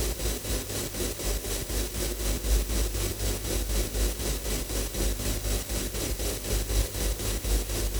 STK_MovingNoiseB-120_03.wav